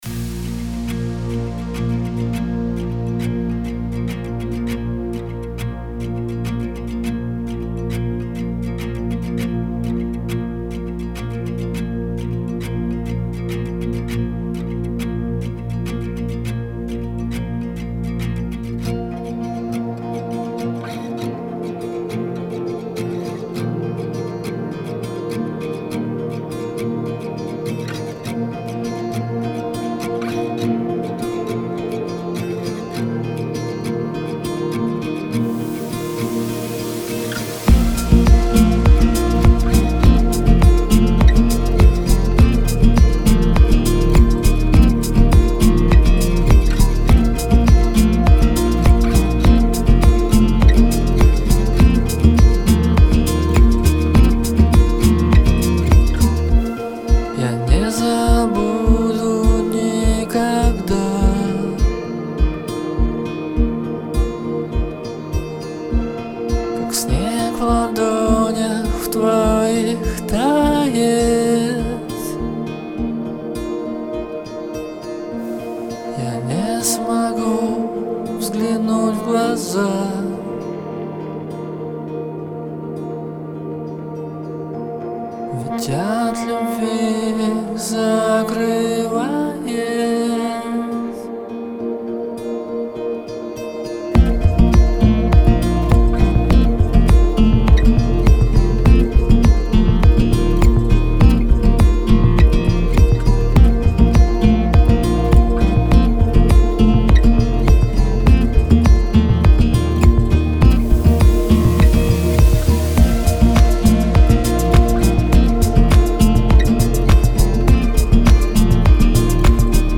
Я не забуду никогда (ДИП\ПОП)
Вот как то в очередной раз нашёл что то старое там Сам сыграл сам спел...
Слишком во многом он меня ограничивал, но я закрывал на это глаза, а теперь и эта проблема со стерео безумный роутинг инструментов и большое количество ревера видимо и даёт грязь и мыло Да, я так и делаю что беру 2 моно трека далее создаю стерео группу и помещаю их туда, но это как бы....наверное получается двойное моно(((